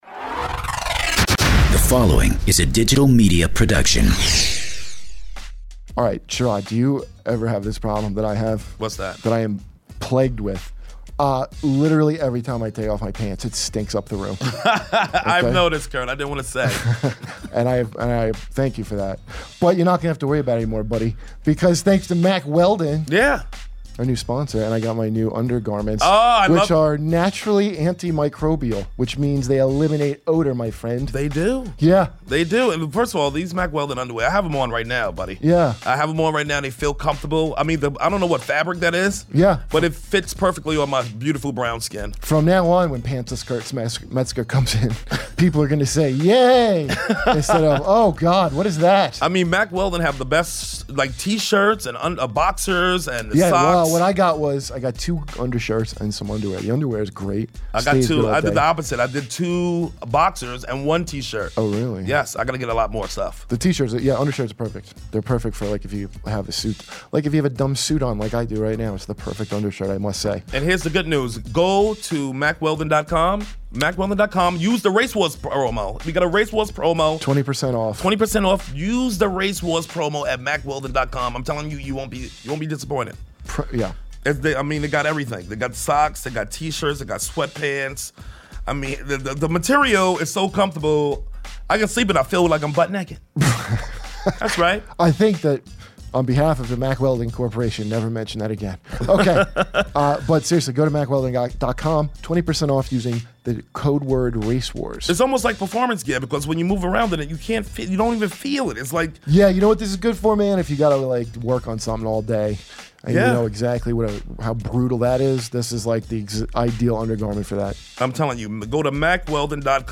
in studio to hash it out